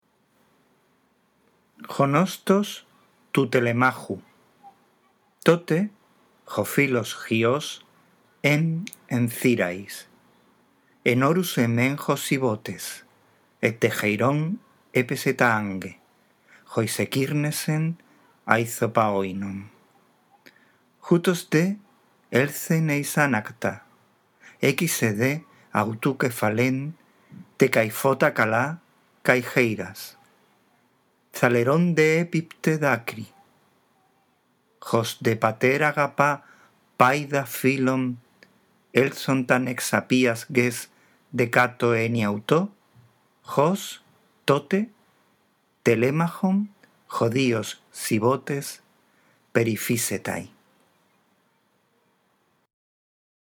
La audición de este archivo te ayudará a mejorar la lectura del griego clásico